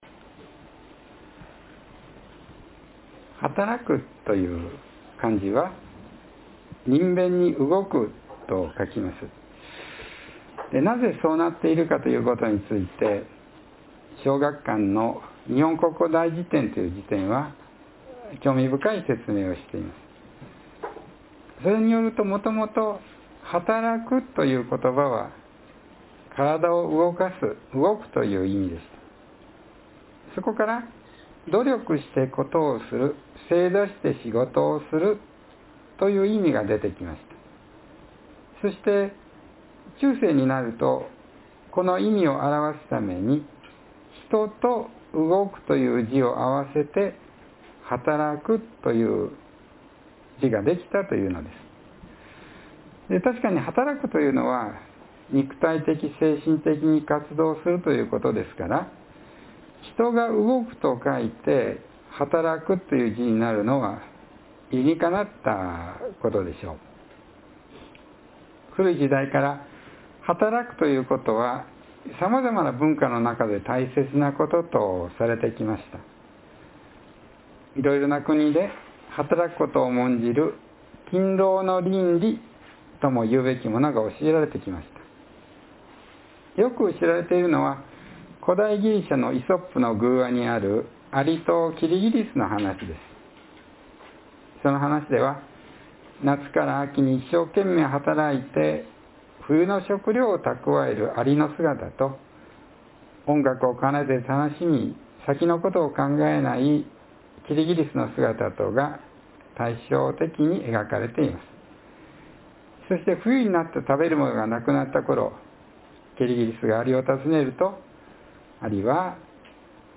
（1月5日の説教より） The kanji (Chinese Character) for the Japanese word “hataraku” (to work) is made up of the kanji “person” and “move.”